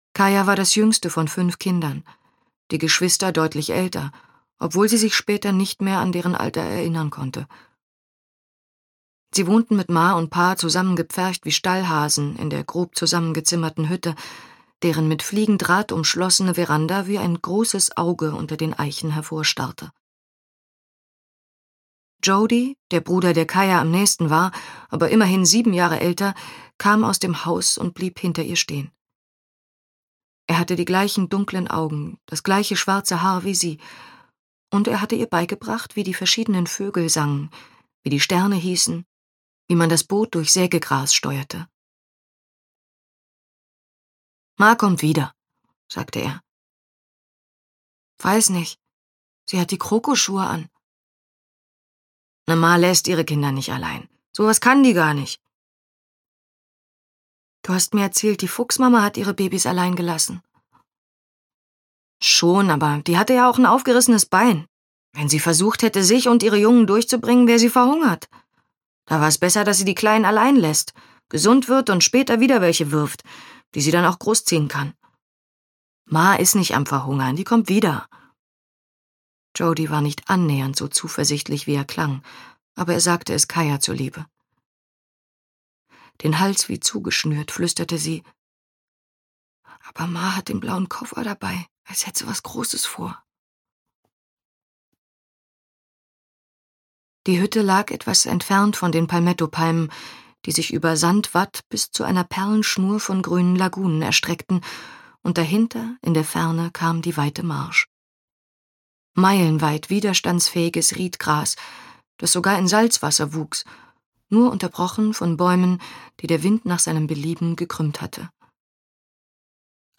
Audio knihaDer Gesang der Flusskrebse (DE)
Ukázka z knihy